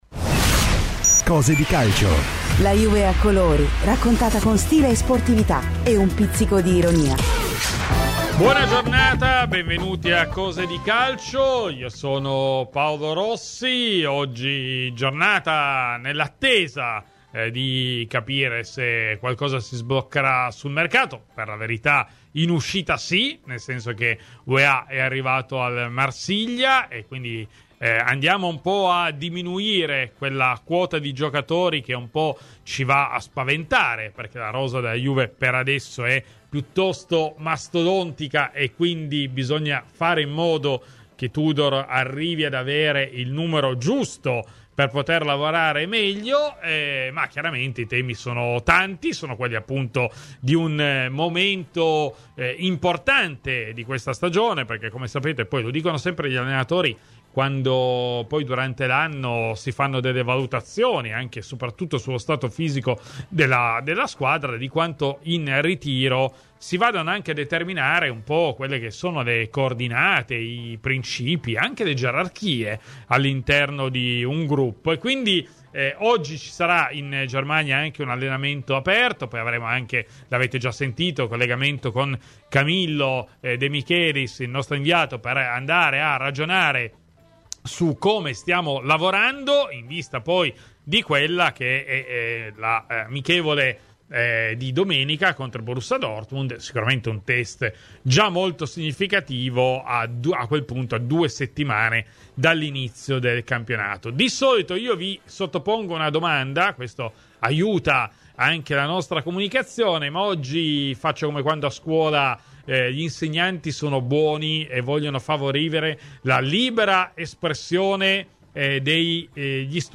In diretta dal ritiro della Juventus a Herzogenaurach